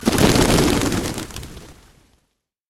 Звуки полета птиц
Пернатые порхают